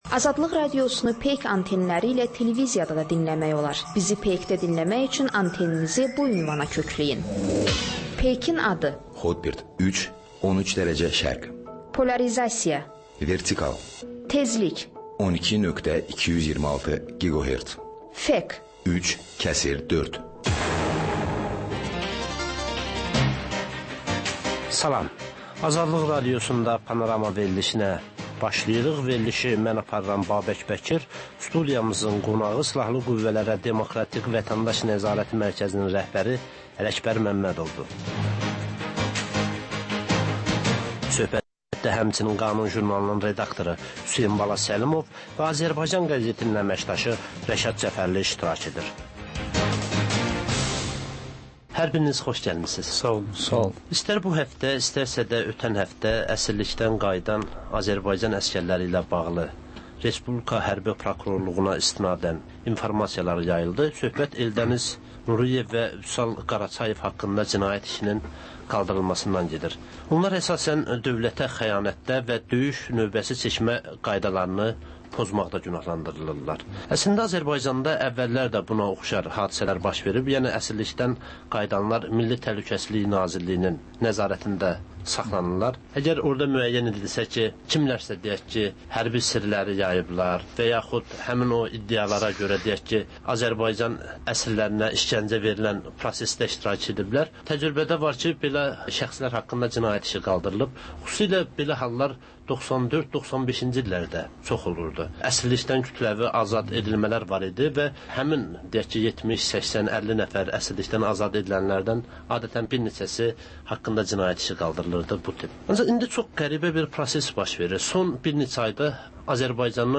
Azərbaycan, Gürcüstan və Ermənistandan reportajlar